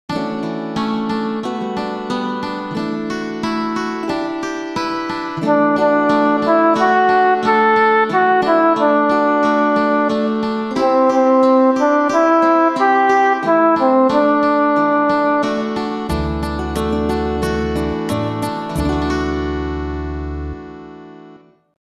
Trinity-Sunday-Canticle.mp3